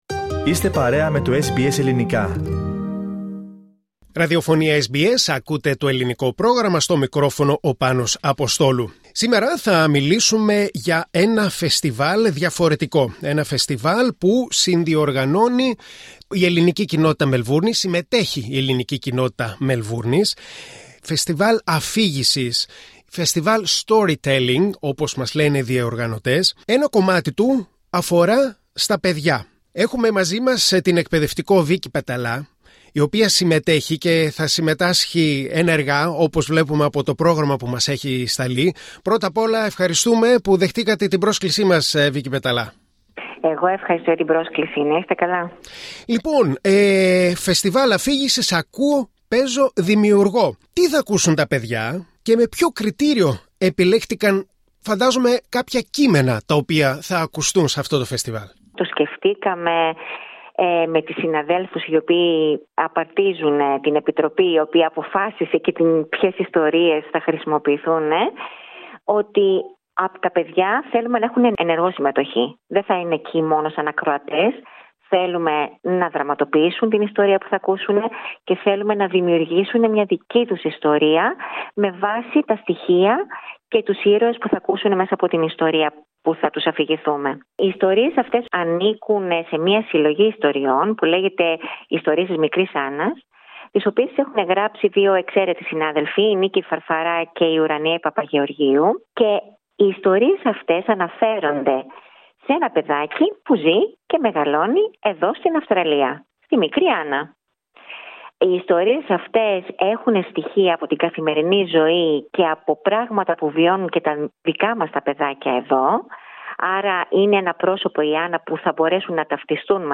Για το Φεστιβάλ Αφήγησης «Ακούω, Παίζω, Δημιουργώ» μίλησε στο SBS Greek